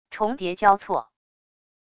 重叠交错wav音频